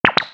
《プニョッなシステム音１》フリー効果音
プニョッという感じの効果音。システム音やプニョッとした時に。